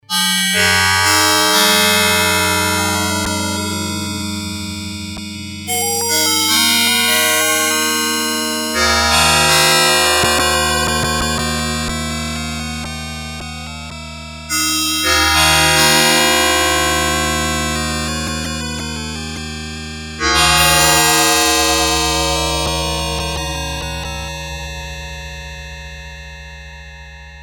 FX: bells chorus mode
noise-chorus-bells.mp3